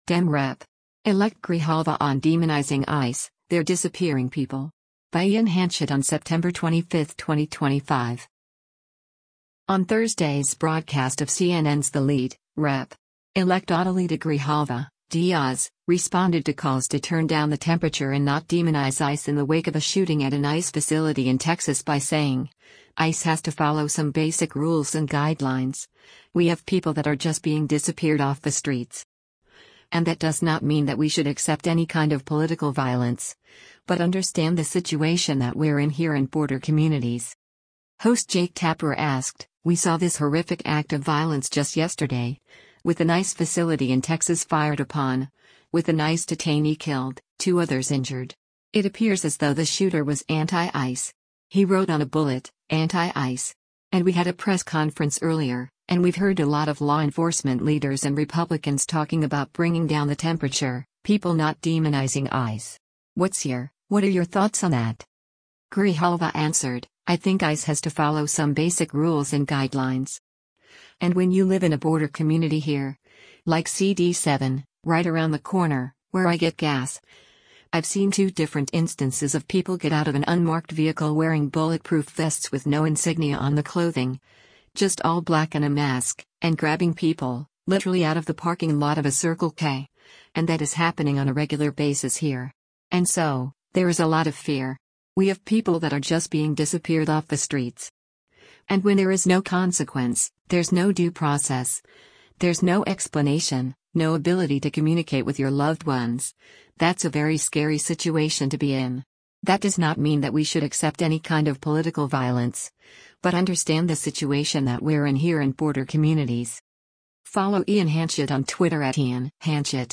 On Thursday’s broadcast of CNN’s “The Lead,” Rep.-Elect Adelita Grijalva (D-AZ) responded to calls to turn down the temperature and not demonize ICE in the wake of a shooting at an ICE facility in Texas by saying, “ICE has to follow some basic rules and guidelines. … We have people that are just being disappeared off the streets.” And “That does not mean that we should accept any kind of political violence, but understand the situation that we’re in here in border communities.”